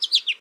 animalia_goldfinch_3.ogg